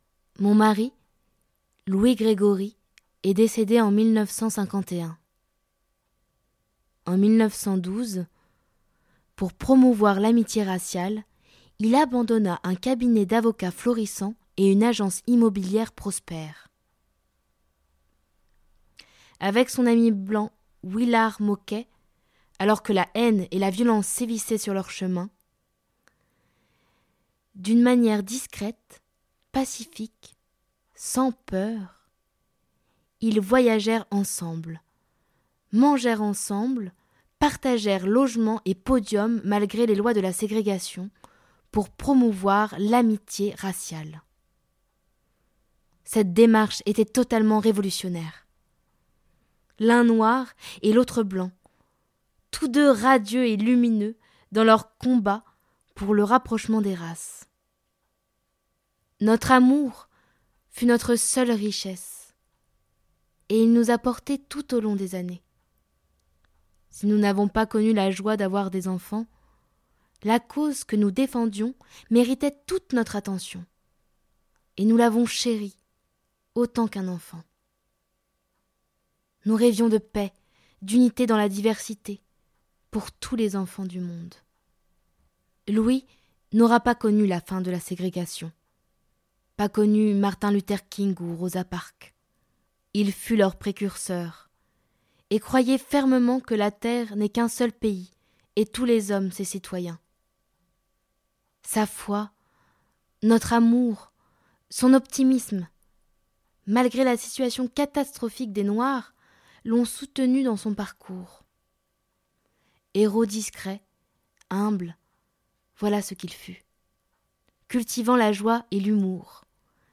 la voix de Louisa